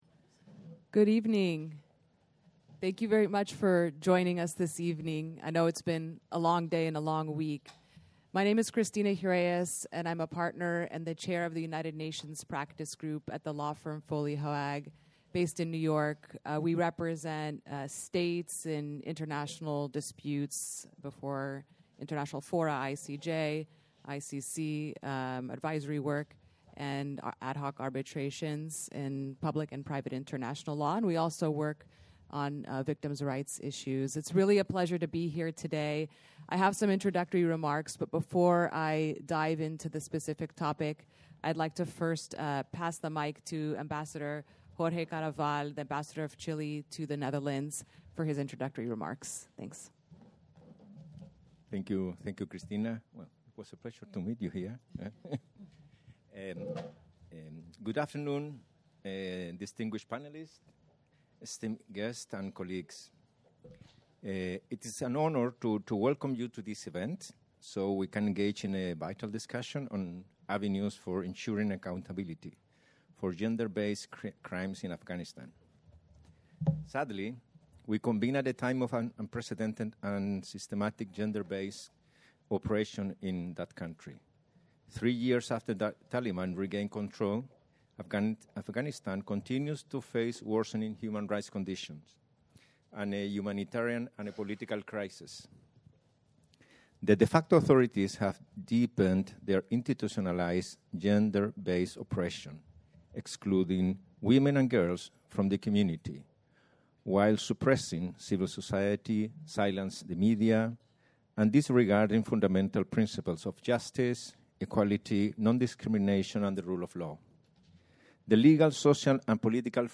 Gender-Justice-event-in-The-Hague-ASP-Dec-2024.mp3